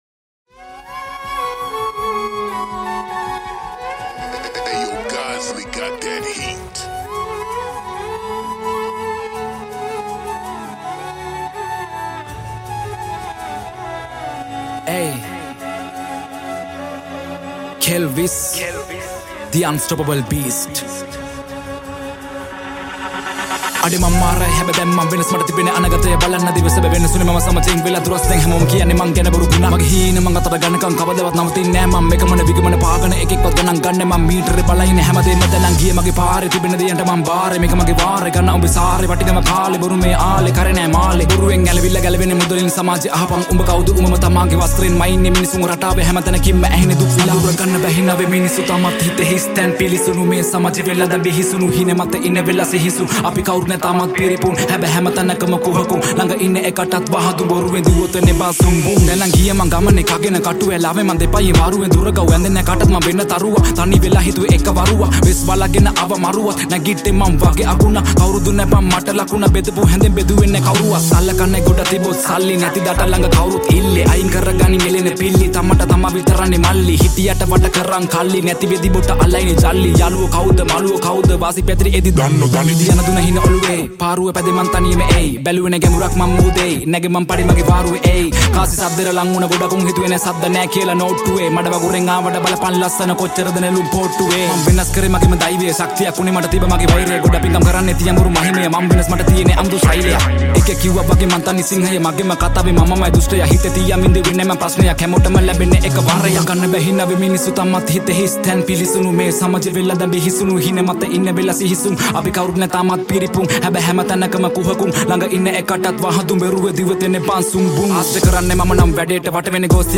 High quality Sri Lankan remix MP3 (3.6).
Rap